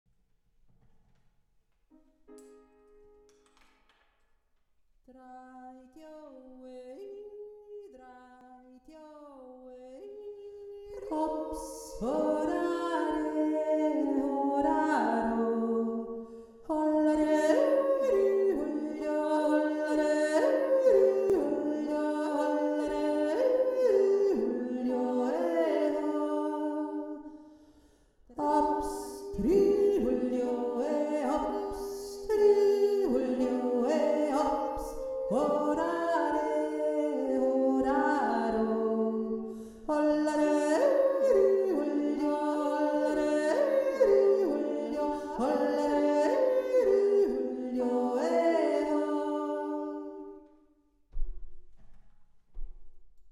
drei-djo-e-i-2-stimme.mp3